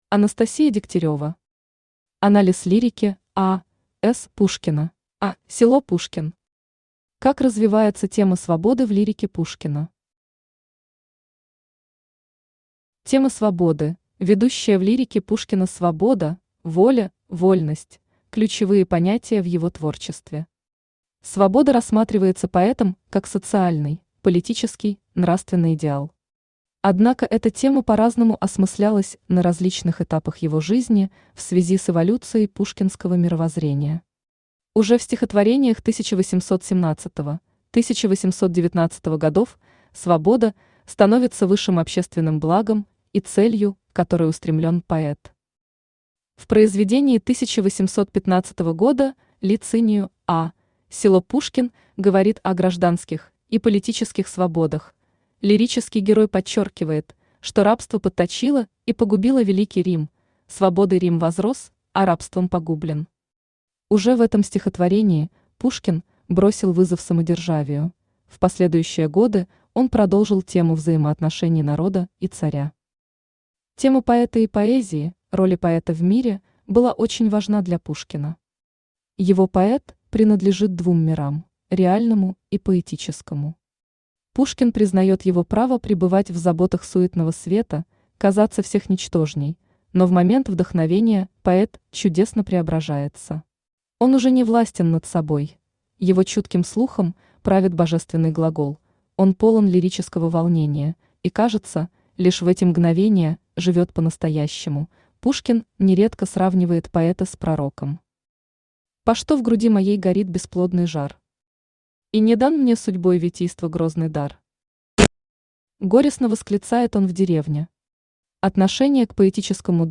Аудиокнига Анализ лирики А.С. Пушкина | Библиотека аудиокниг
Читает аудиокнигу Авточтец ЛитРес.